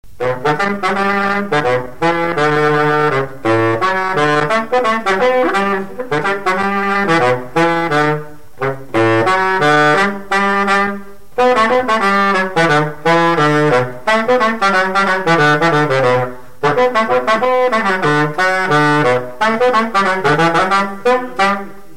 Scottish
Pièce musicale inédite